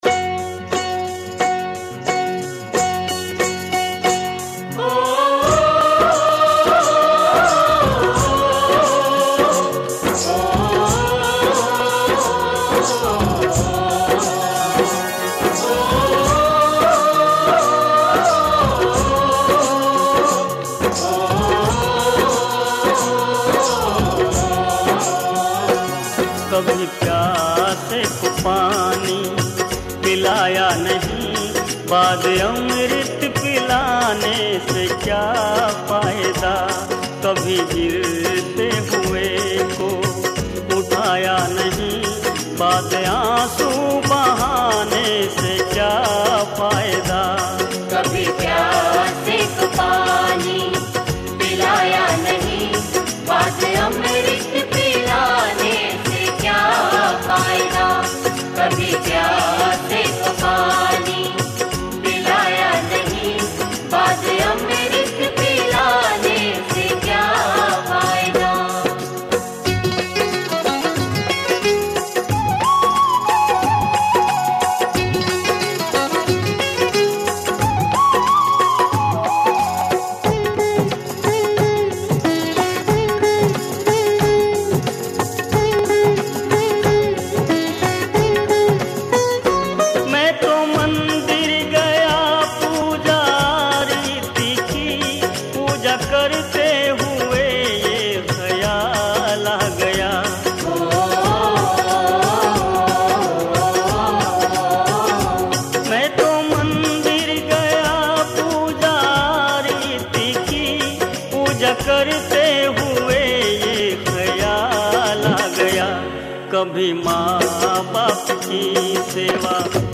भजन